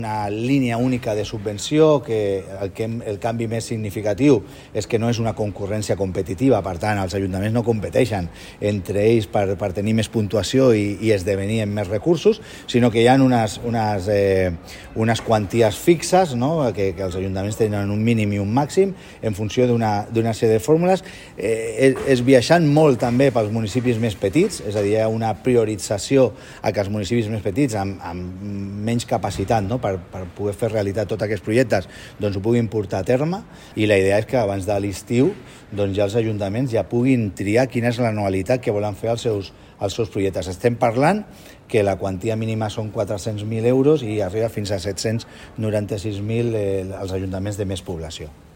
El secretari de Governs Locals i Relacions amb Aran destaca que abans de l’estiu els ajuntaments ja podran escollir l’anualitat dels seus projectes…